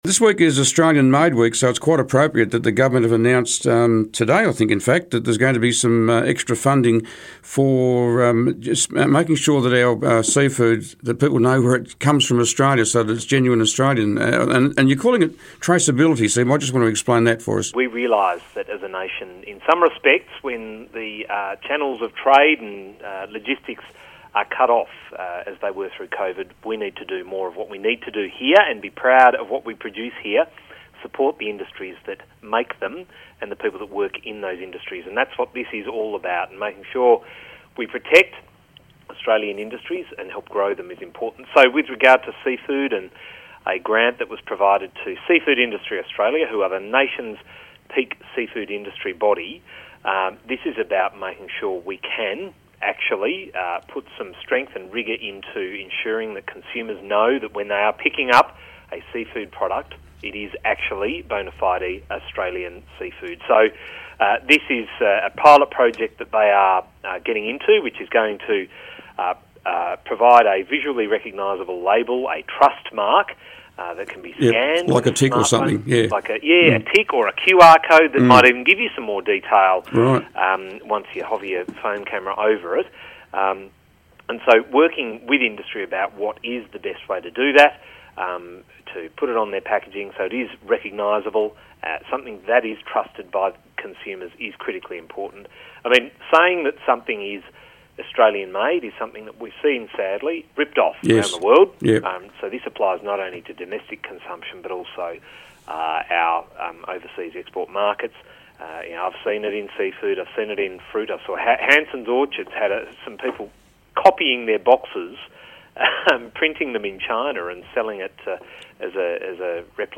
Senator Jonathon Duniam stepped up onto the Pollies Perch today.